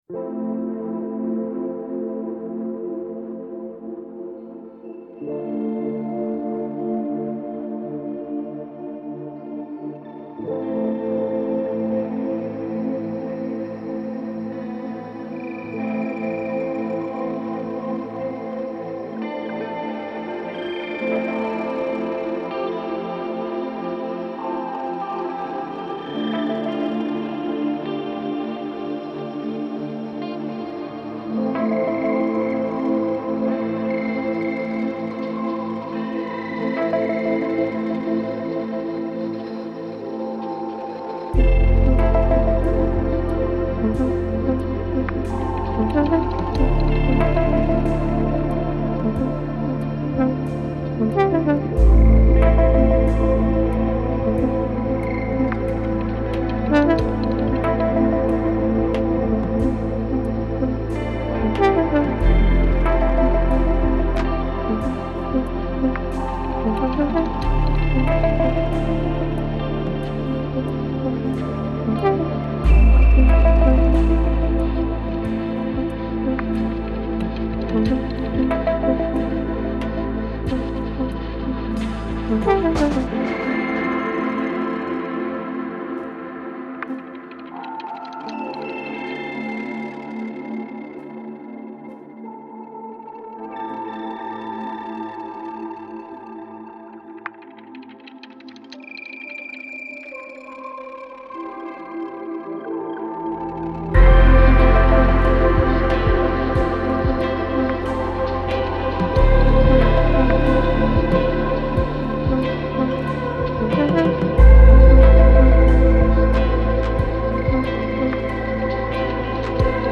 Hazy pop with ambient textures and moody themes.